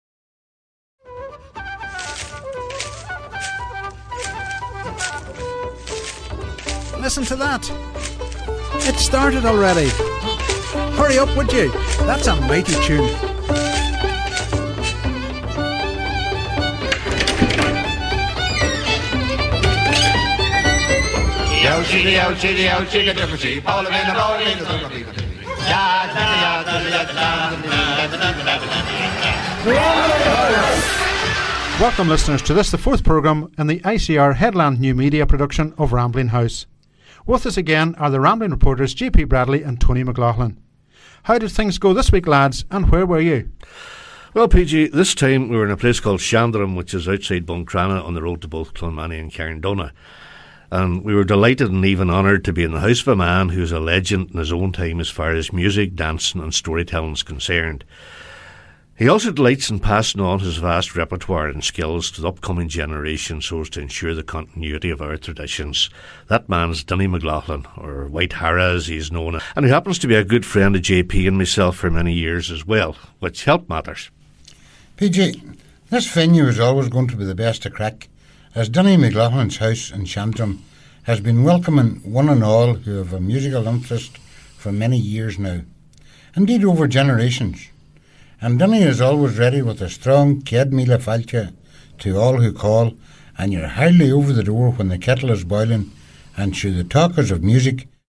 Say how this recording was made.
The studio sets the tone for the night to come in Shandrum outside Buncrana, Co. Donegal